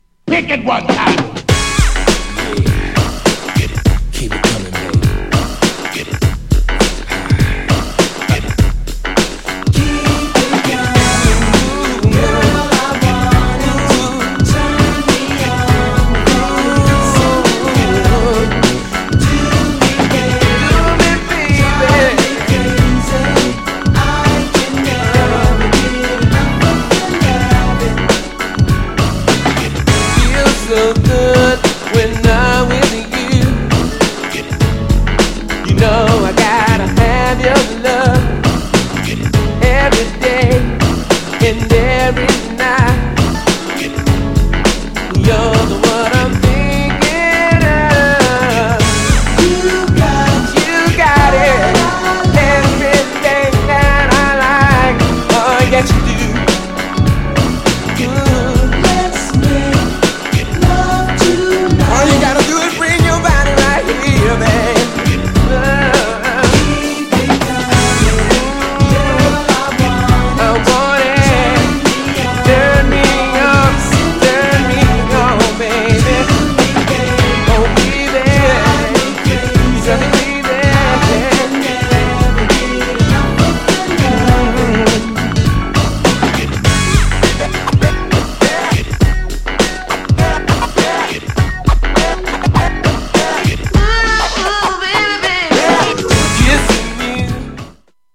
自身のプロデュースによるメロディアスなNEW JACK!!
US12" MIXとHIP HOP MIXもグルーブがあって最高!!
GENRE R&B
BPM 106〜110BPM